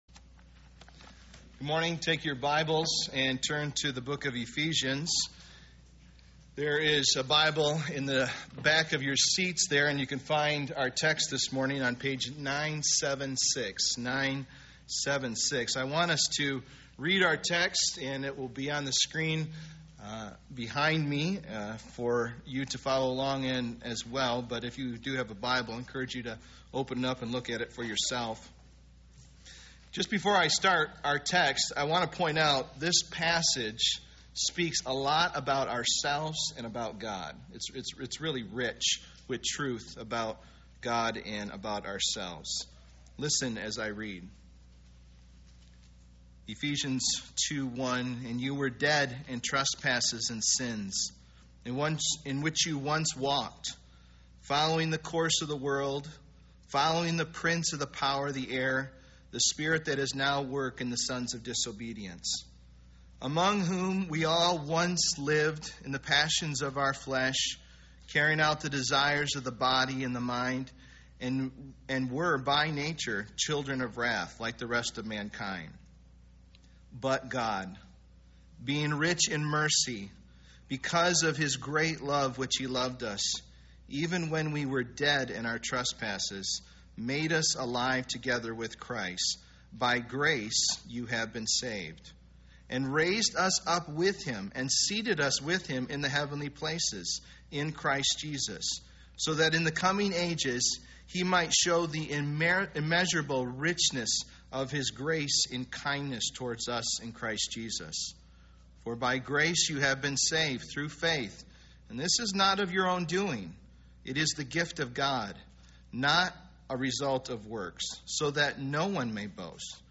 Passage: Ephesians 2:1-10 Service Type: Sunday Morning Bible Text